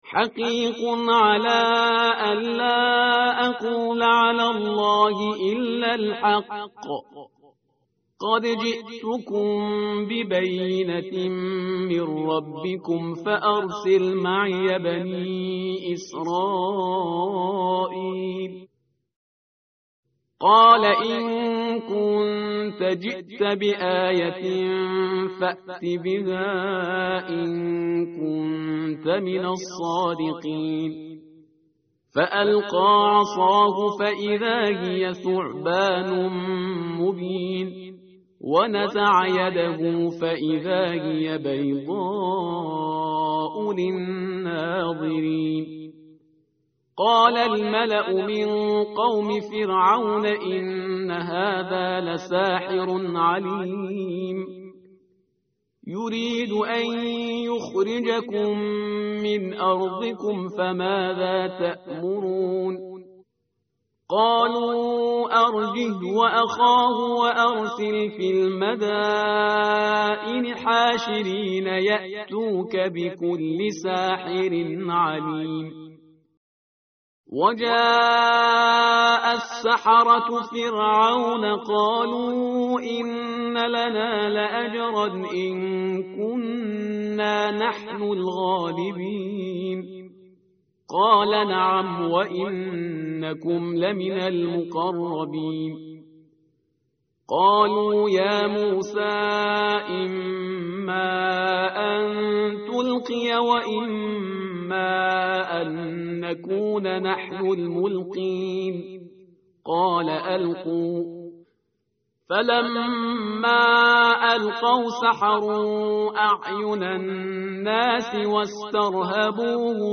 tartil_parhizgar_page_164.mp3